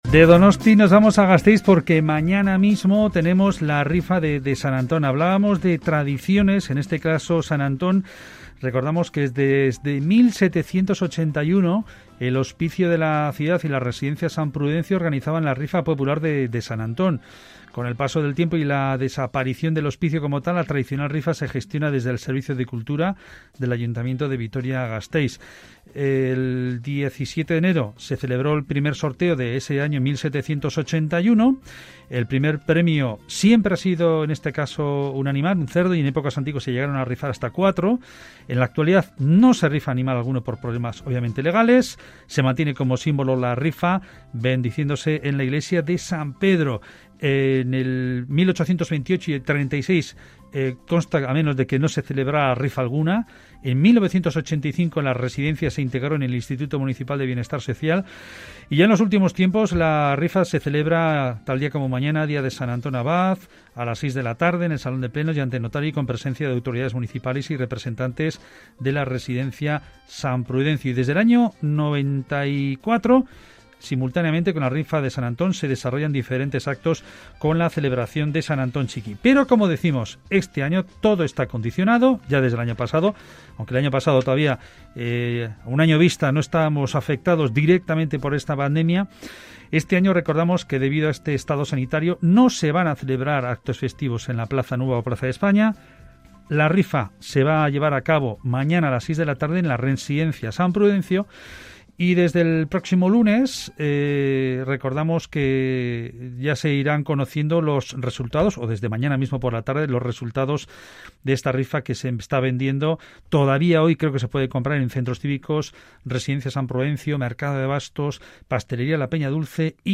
Rifa de San Antón desde el emblemático Bar “La Unión-Byra” de Vitoria-Gasteiz